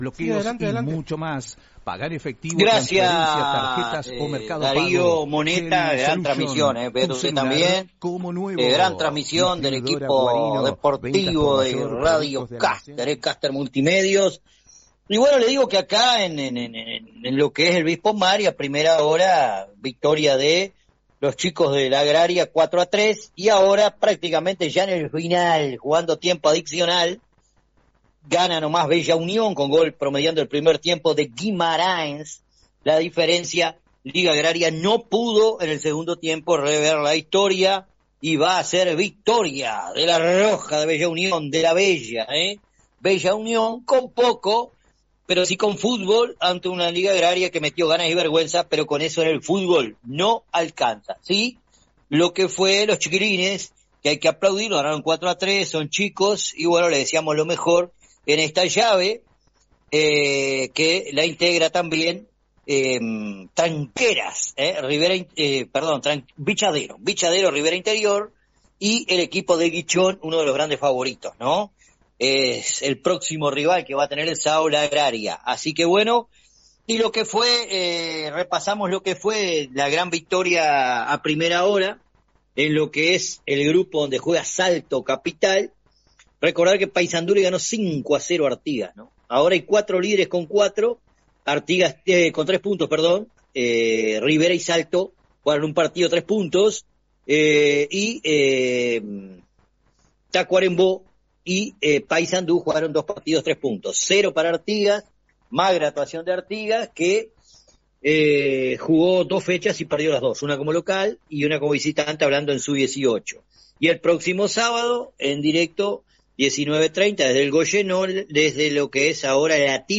Comentarios de cierre de la jornada